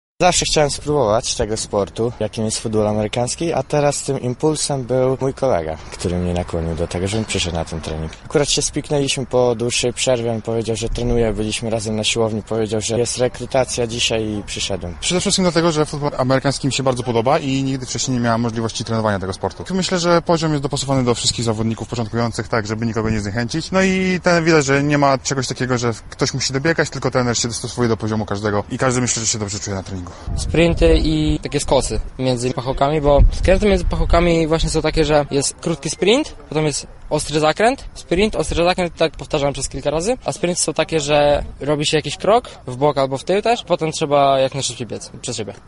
O tym dlaczego zdecydowali się wziąć udział w rekrutacji oraz co sprawiło im największe trudności mówią – być może – przyszli zawodnicy Tytanów.
Sonda-1.mp3